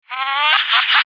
Spooky Halloween Sound Effects
evil-laughter-6-reverse-real.mp3